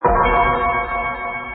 Play Irregular Chord Sound - SoundBoardGuy
Play, download and share Irregular Chord Sound original sound button!!!!